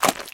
STEPS Swamp, Walk 12.wav